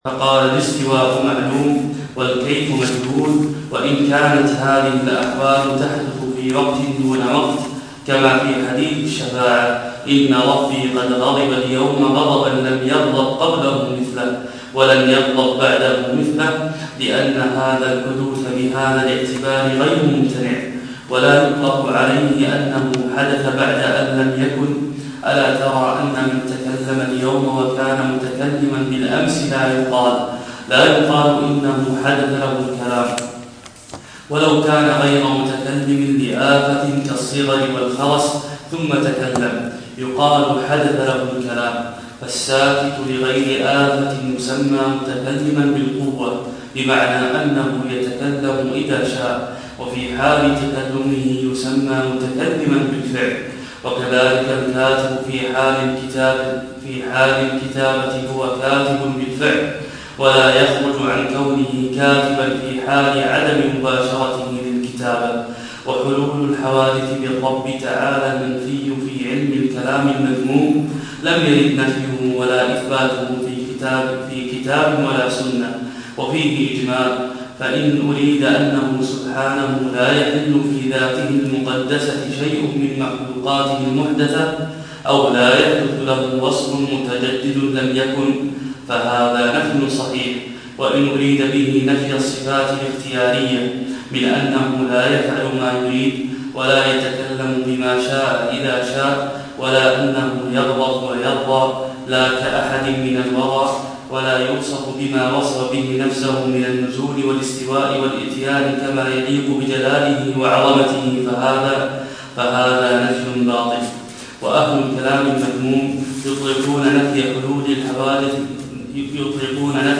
شرح كتاب ابن أبي العز على الطحاوية